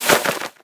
Minecraft Version Minecraft Version snapshot Latest Release | Latest Snapshot snapshot / assets / minecraft / sounds / mob / bat / takeoff.ogg Compare With Compare With Latest Release | Latest Snapshot
takeoff.ogg